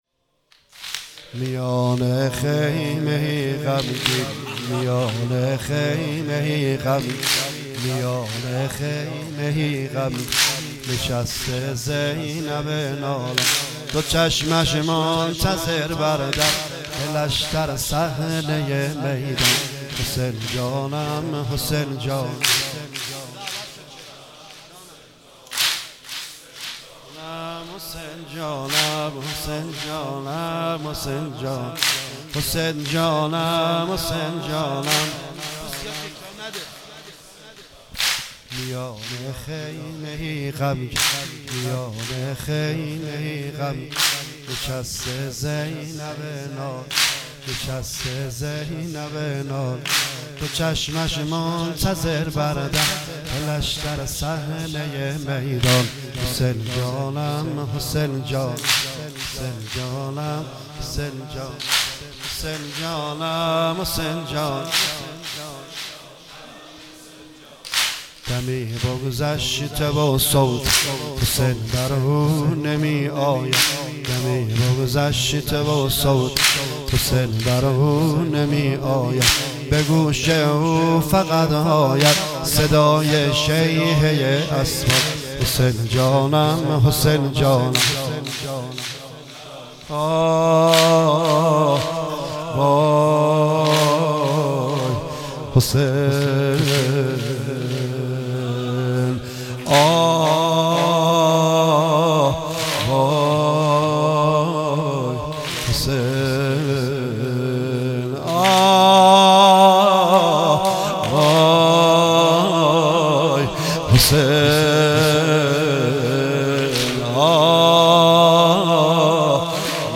23 مرداد 96 - هیئت ریحانة - واحد - میان خیمه ای غمگین